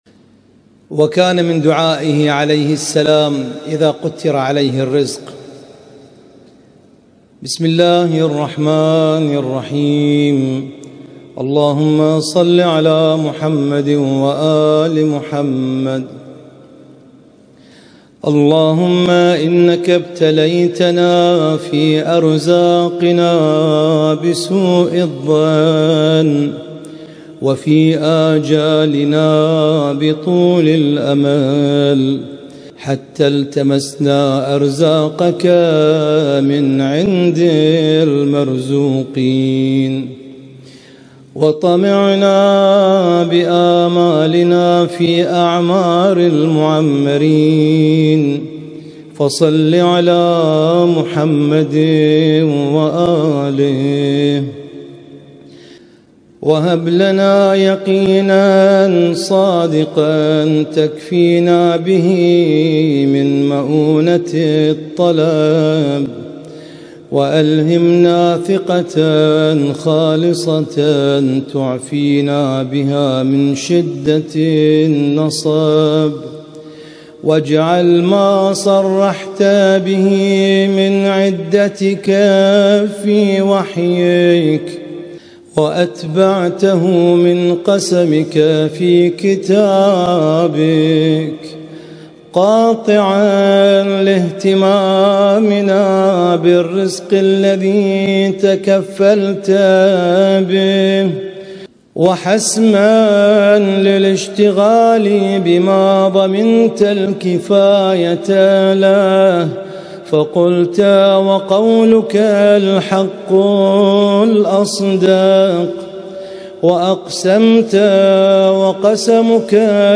القارئ:
اسم التصنيف: المـكتبة الصــوتيه >> الصحيفة السجادية >> الادعية السجادية